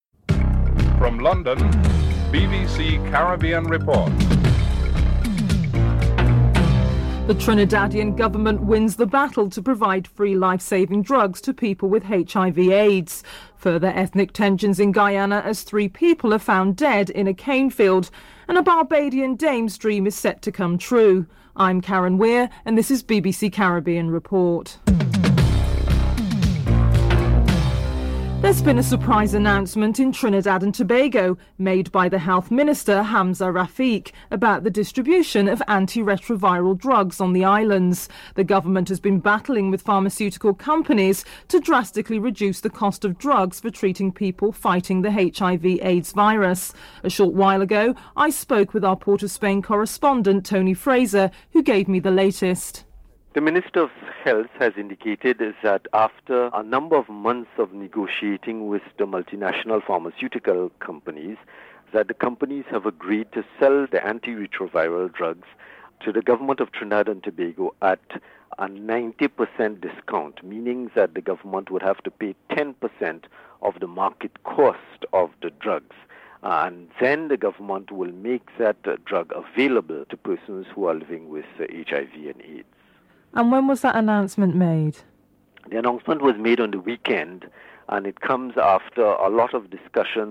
1. Headlines (00:00-00:27)